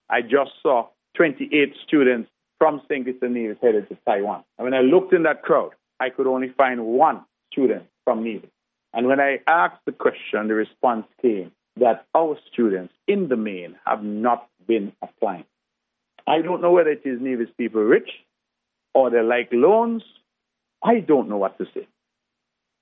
That was Premier, the Hon. Mark Brantley, commenting on the apparent number of Nevisian students among the most recent cohort of Taiwanese Scholarship recipients.